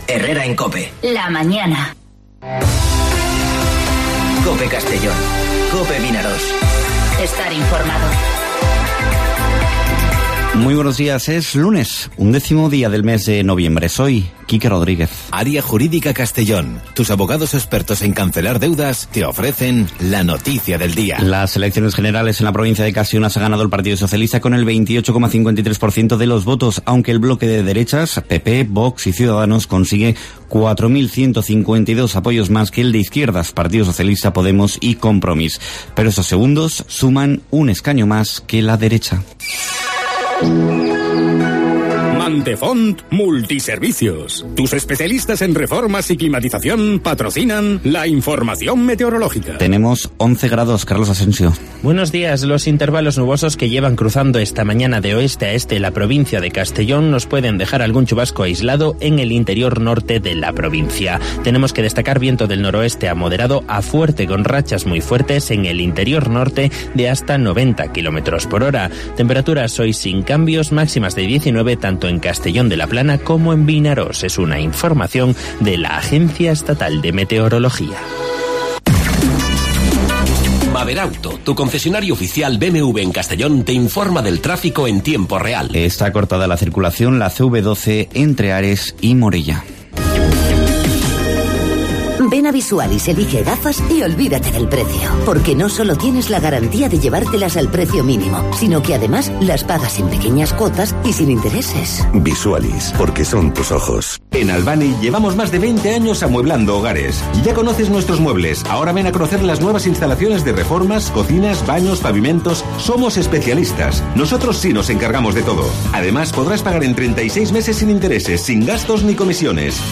Informativo Herrera en COPE Castellón (11/10/2019)